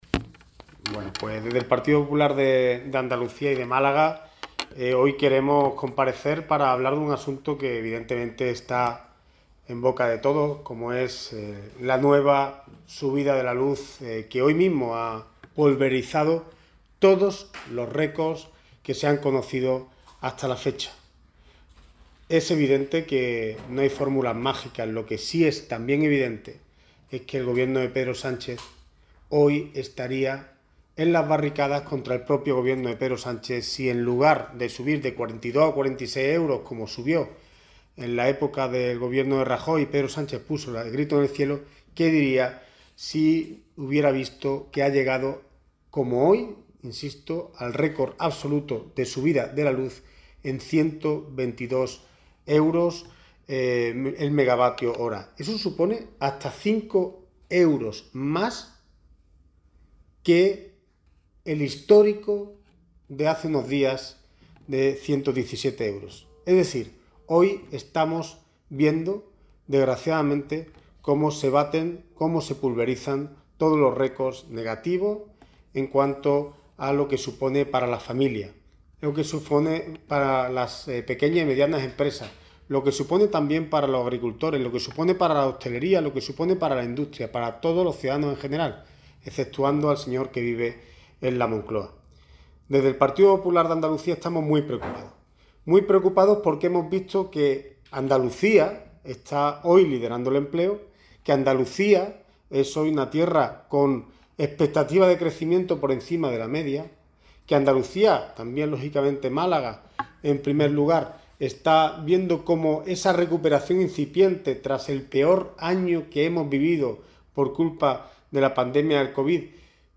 En rueda de prensa en Málaga, Carmona aseguró que si Sánchez fuera coherente “el mismo estaría en las barricadas contra su propio Gobierno” recordando “cuando puso el grito en el cielo cuando subió el precio de la energía con Rajoy, pero a un precio muchísimo más bajo que el actual”.